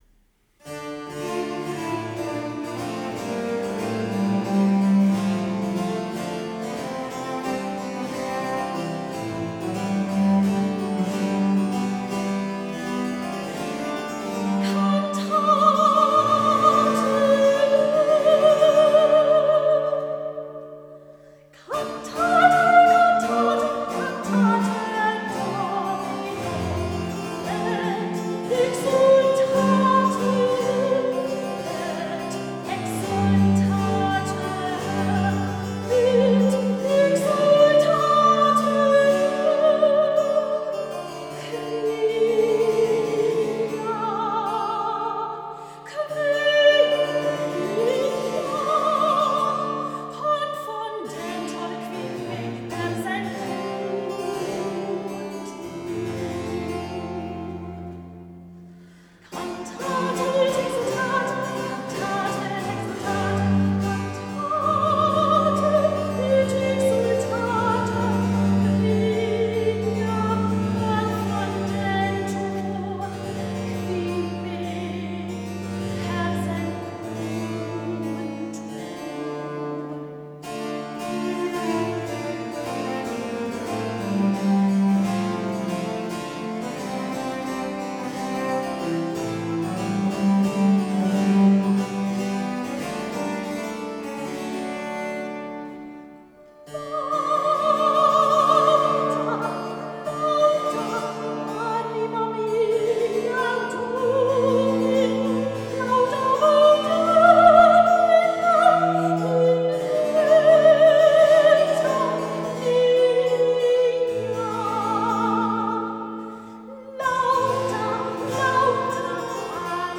Sopran
Cembalo
Barockcello